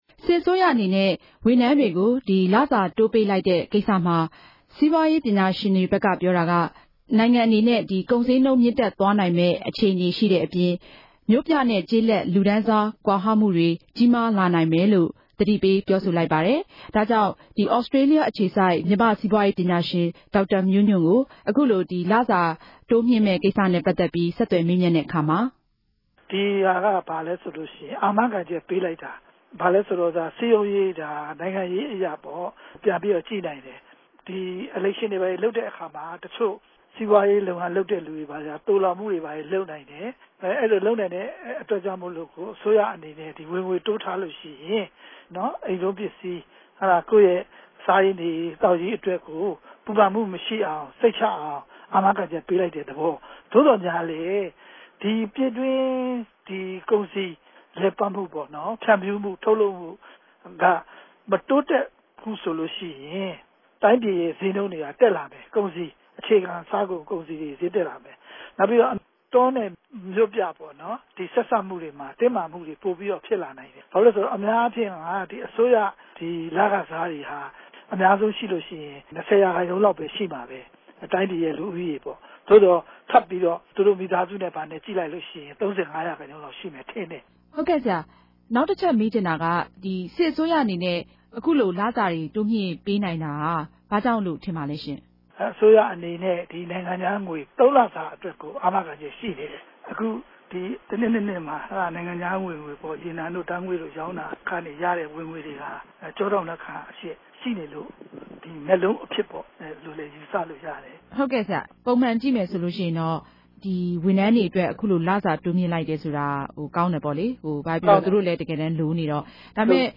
မေးူမန်းတင်ူပခဵက်။